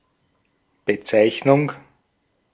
Ääntäminen
US : IPA : [neɪm]